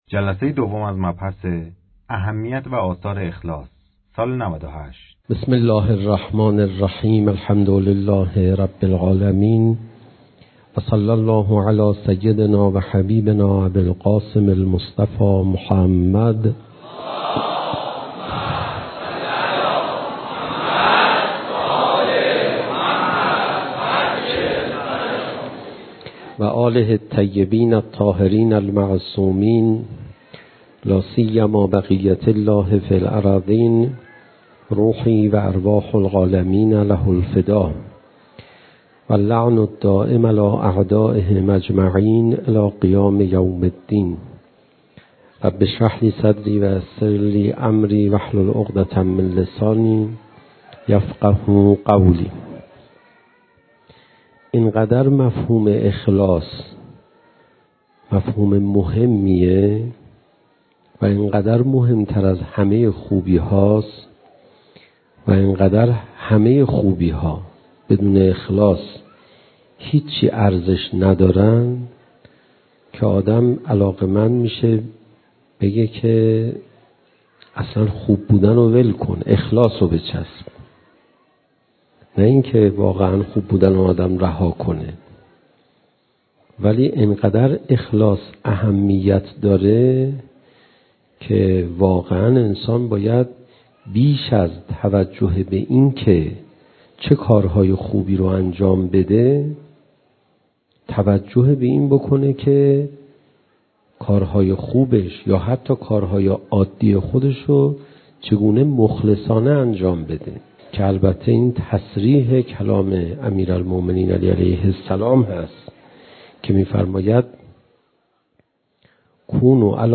صوت | اهمیت و آثار اخلاص (مسجد سیدالشهدا(ع) - فاطمیه98 )